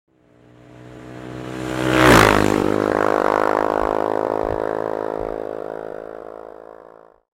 جلوه های صوتی
دانلود صدای موتور 14 از ساعد نیوز با لینک مستقیم و کیفیت بالا